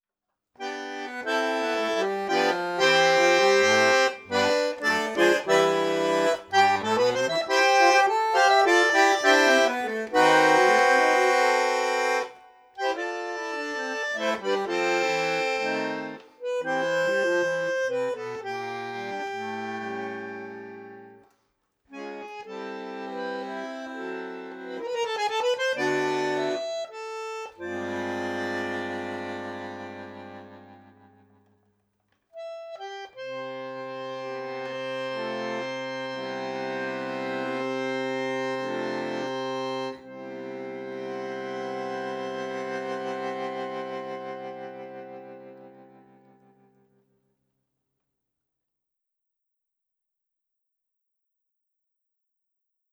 La Casita de mis viejos - bandonéon solo [ ÉCOUTER ] 6.